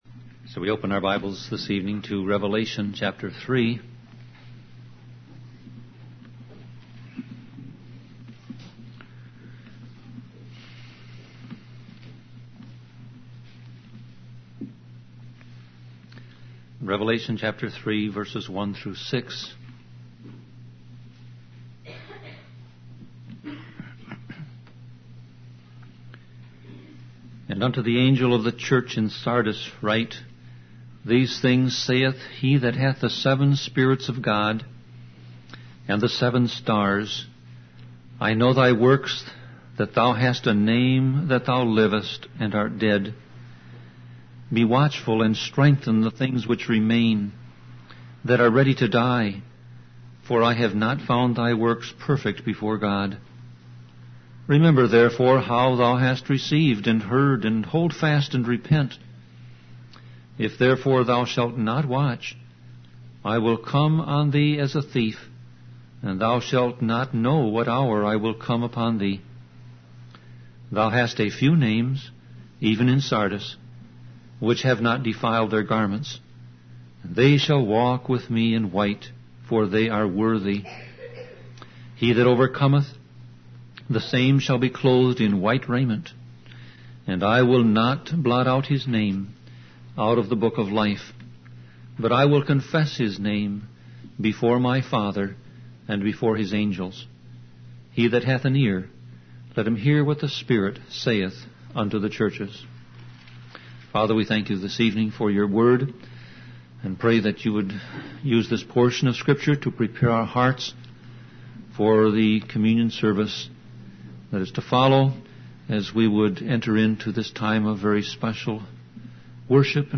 Sermon Audio Passage: Revelation 3:1-6 Service Type